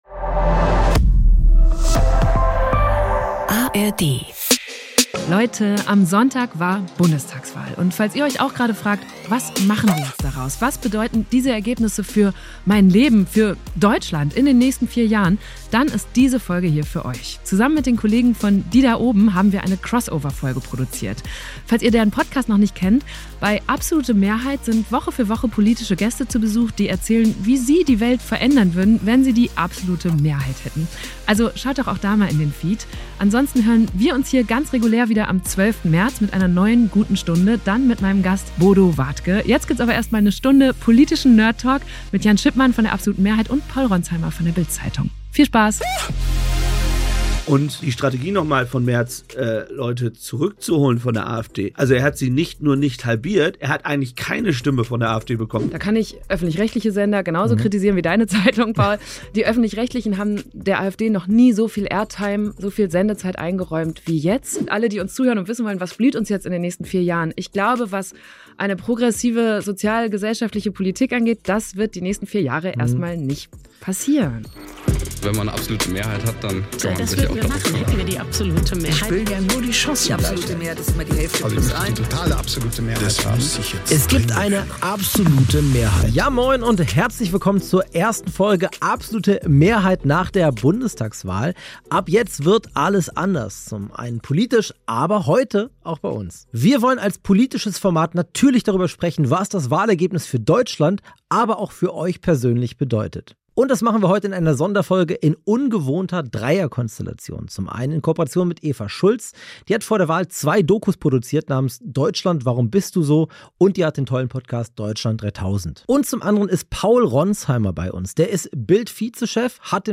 +++ Ausgezeichnet als bester Deutscher Interview-Podcast 2020 +++